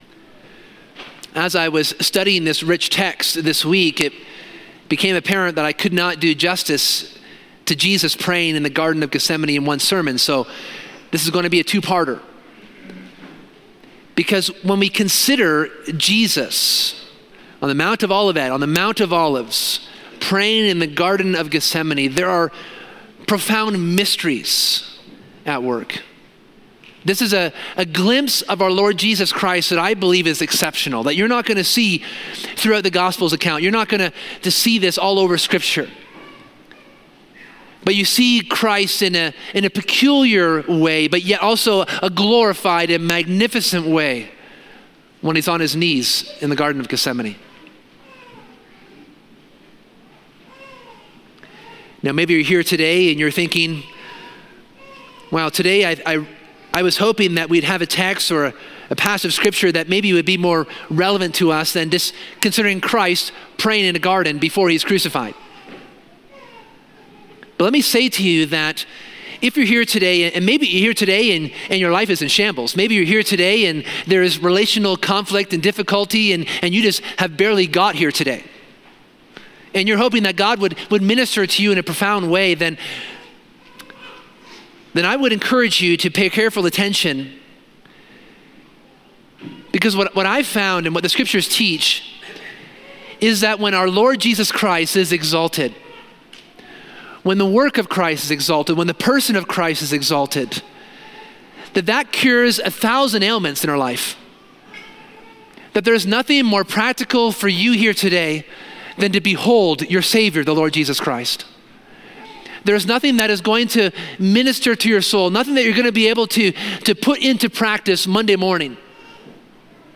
This sermon explores the profound mystery of Jesus in the Garden of Gethsemane, focusing on two key lessons: the humanity of Christ and the immense cost of salvation.